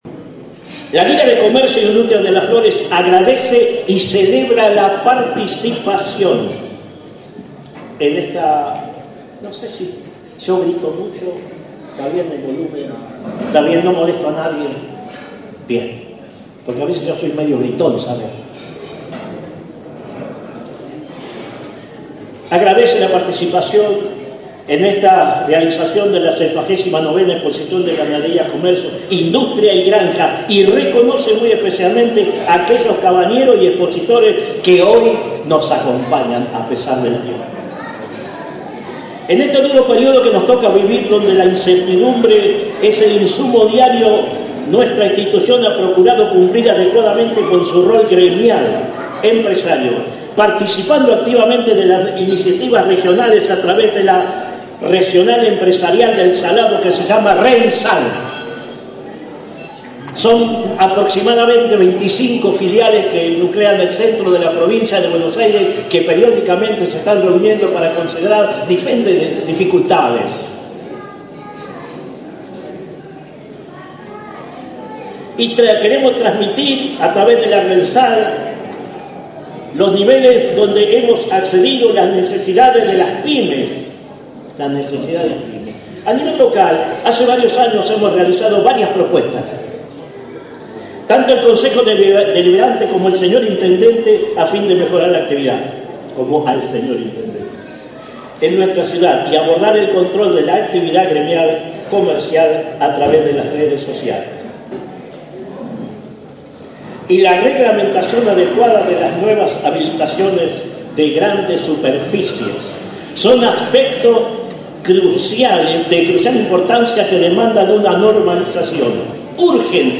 Difundimos dos de los discursos pronunciados en la inauguración de la expo rural 2025.